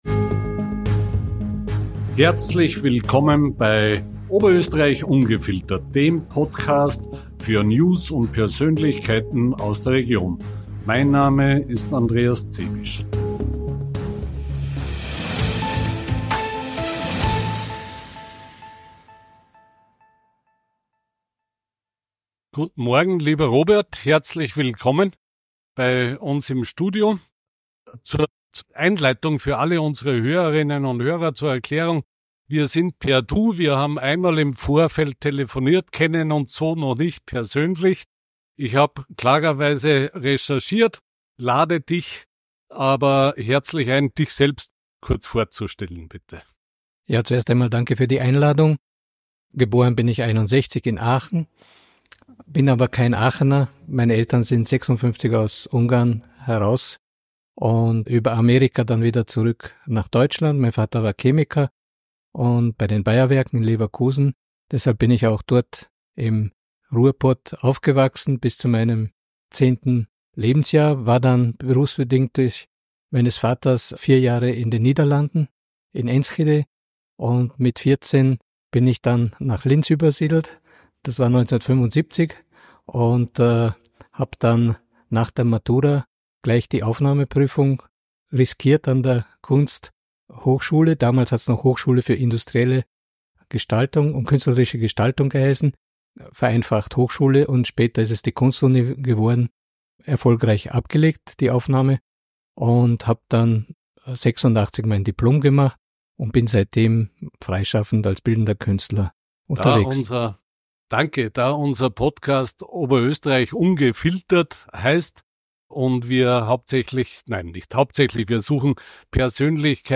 Ein Insidergespräch ~ OÖ ungefiltert Podcast